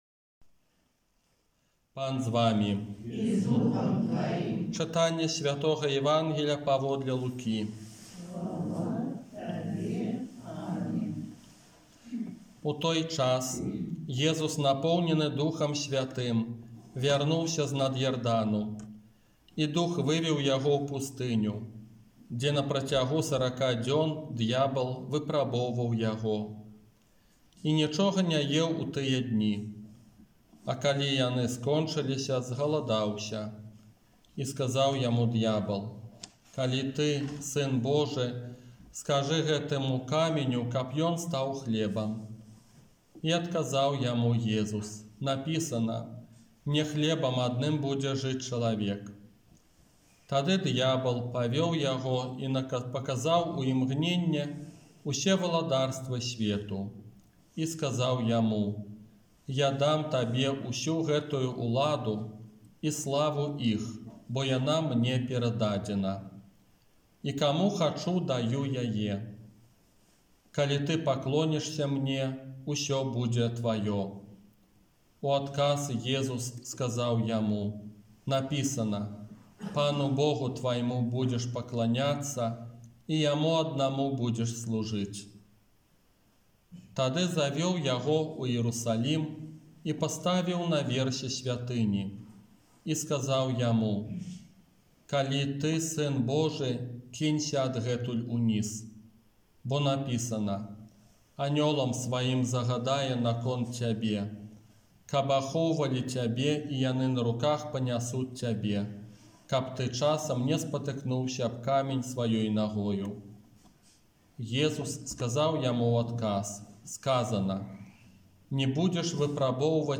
ОРША - ПАРАФІЯ СВЯТОГА ЯЗЭПА
Казанне на першую нядзелю Вялікага Посту 6 сакавіка 2022 г.